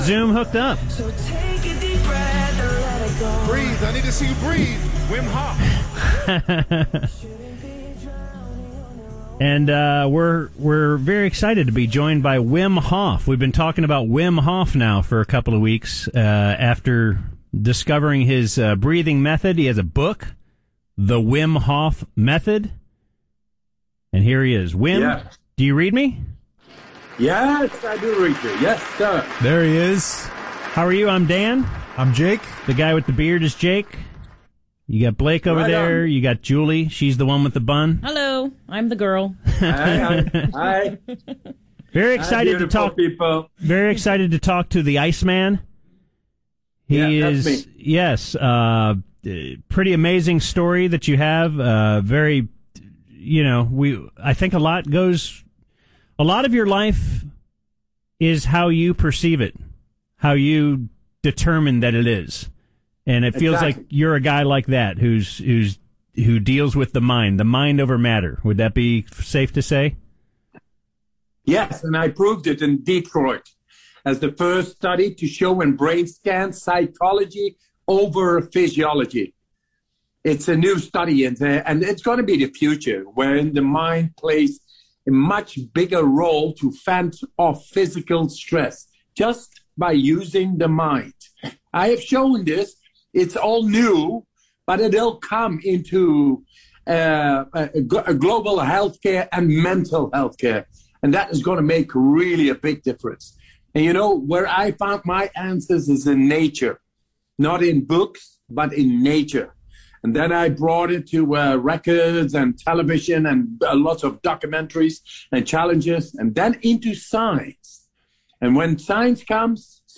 The Hang Zone - Wim Hof Interview - 5.28.21 - The UnTicket